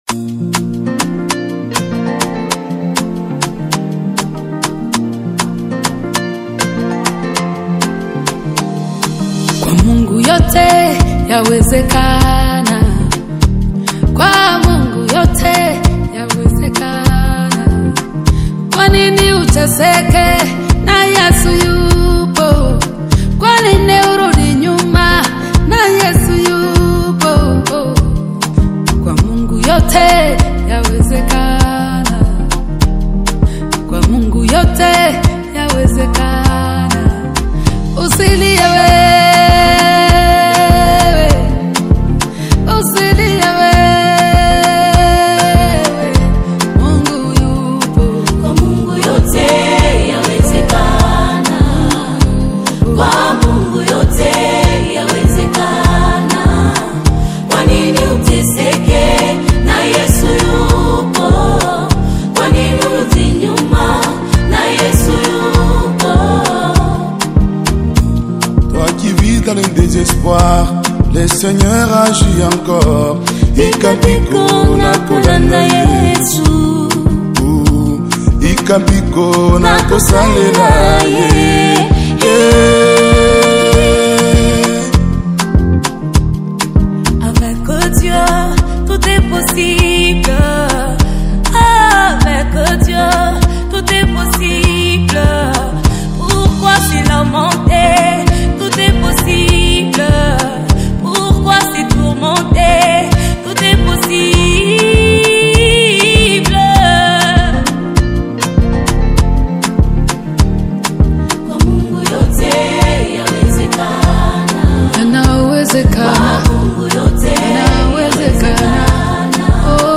gospel singers
The song begins with a soft piano intro